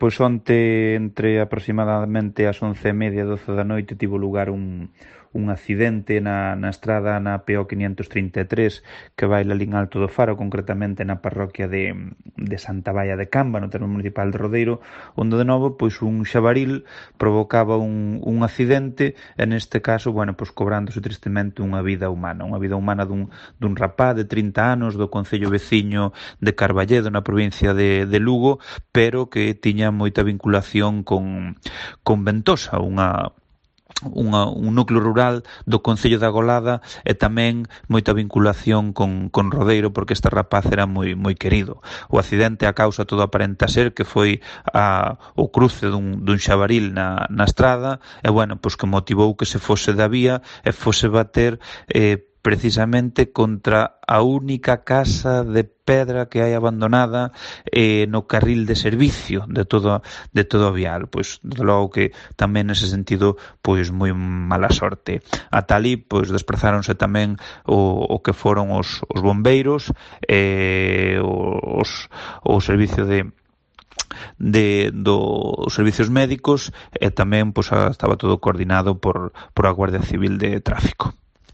El alcalde de Rodeiro, Luis López, describe el accidente mortal en Alto do Faro